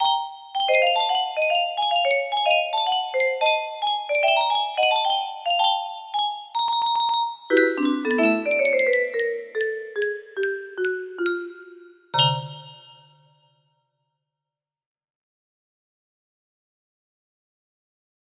Derya music box melody